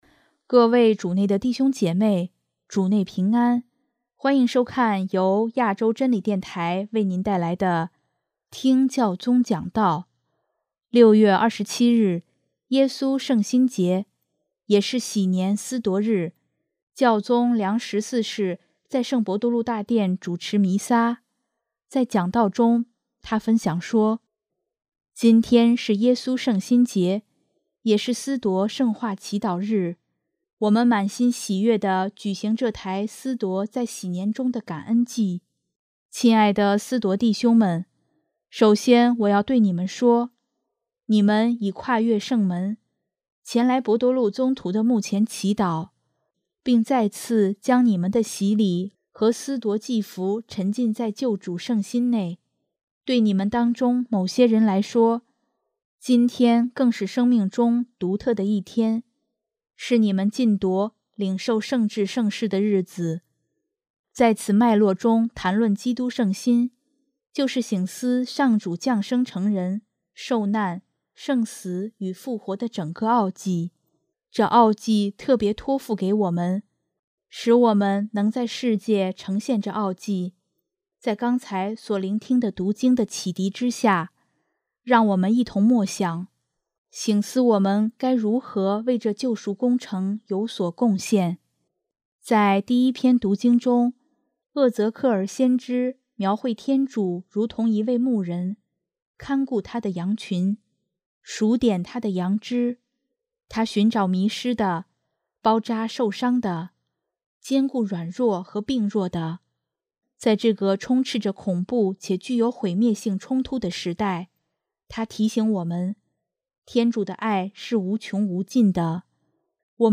6月27日，耶稣圣心节，也是禧年司铎日，教宗良十四世在圣伯多禄大殿主持弥撒，在讲道中，他分享说：